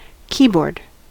keyboard: Wikimedia Commons US English Pronunciations
En-us-keyboard.WAV